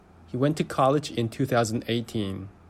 2018-pronunciation.mp3